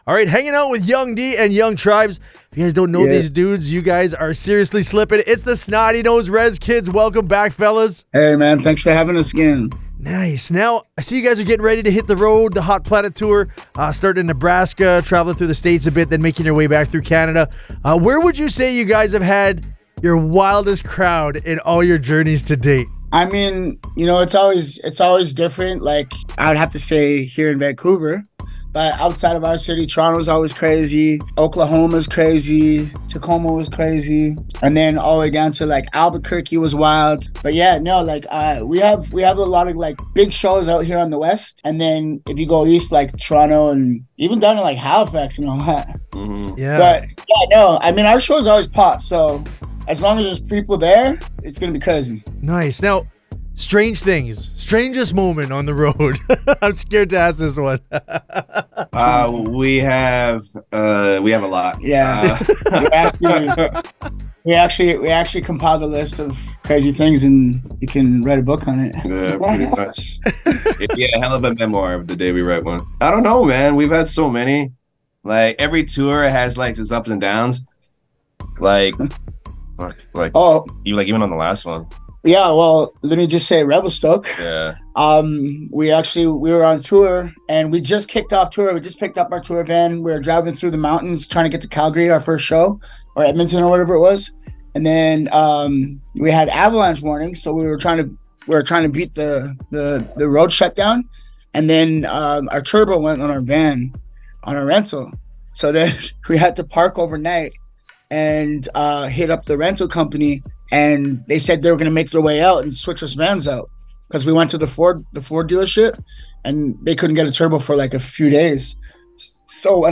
Interview with the Snotty Nose Rez Kids
snrk-full-for-webs-with-music.mp3